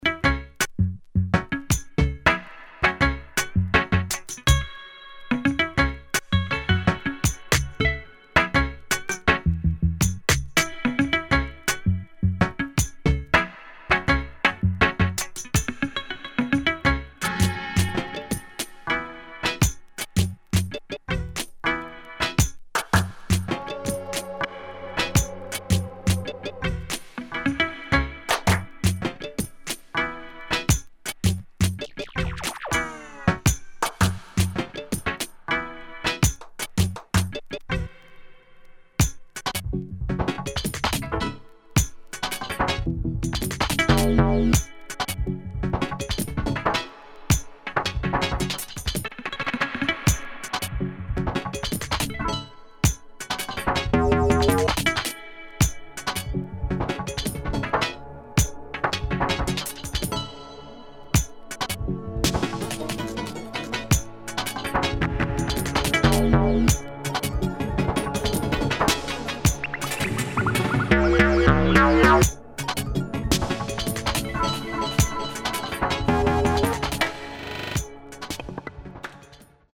[ EXPERIMENTAL / DOWNBEAT ]